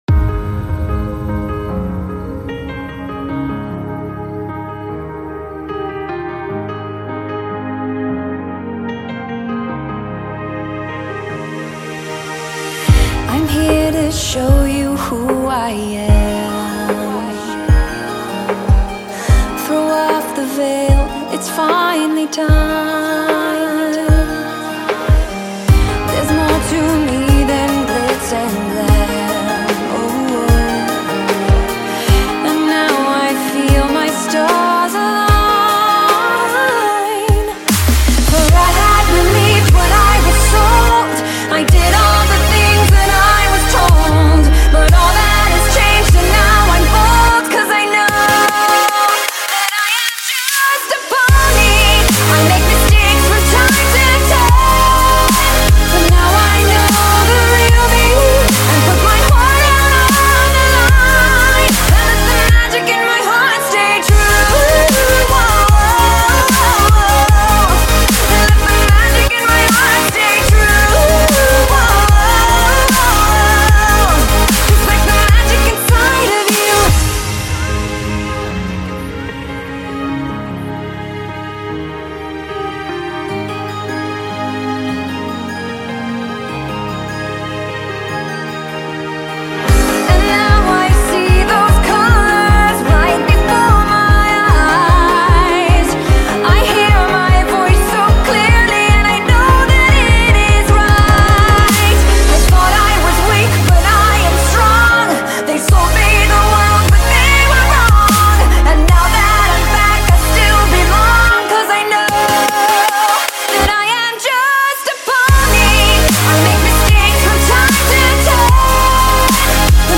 Total time spent re mastering - 4 hours